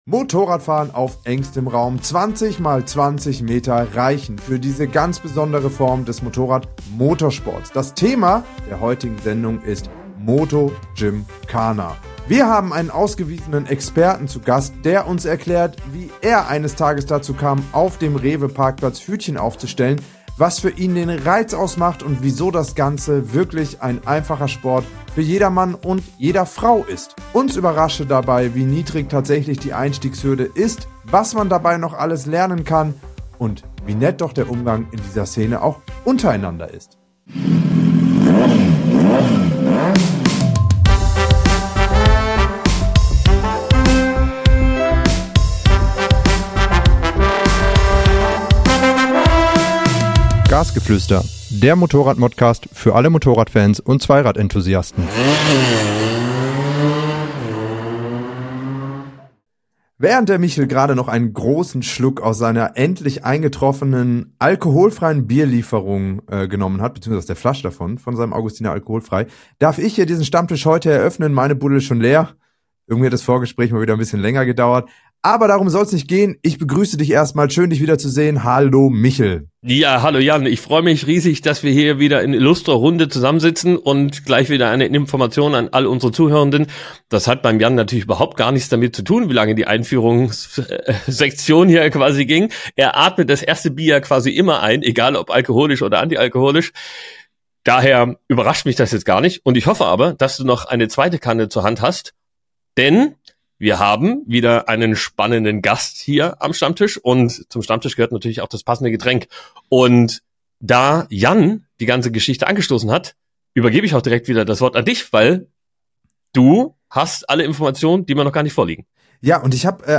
Anregende Diskussionen, packende Einblicke hinter die Kulissen der Motorradbranche gepaart mit einer guten Prise Humor und Ironie sollen allen Motorradfahrern die Zeit versüßen, in der sie selbst nicht auf ihrem Bike sitzen können.